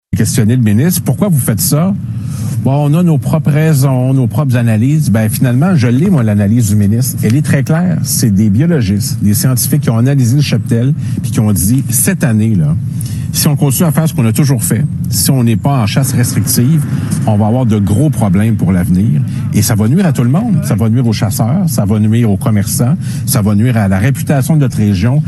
Notez bien que l’extrait suivant a été réalisé à partir d’un reportage de TVA, mais qu’il a été rendu public sur YouTube : voici Pascal Bérubé :